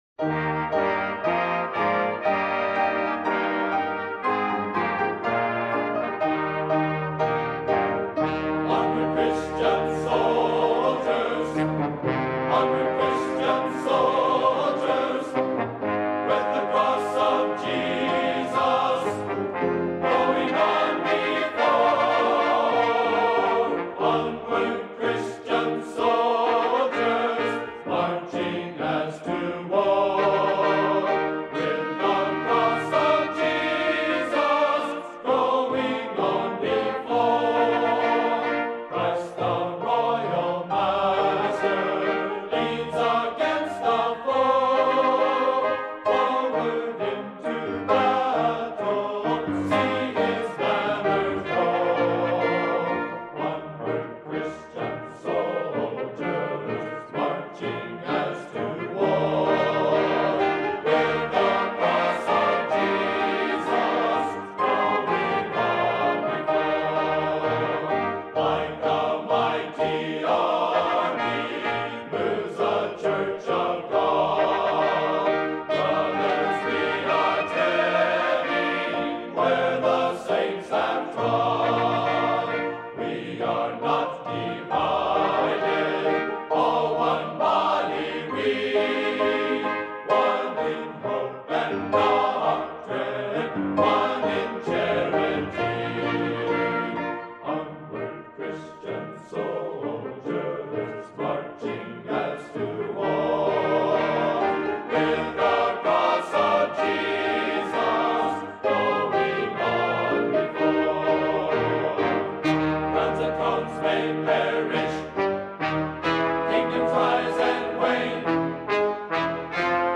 Members of the chorus come from twenty states and Canada.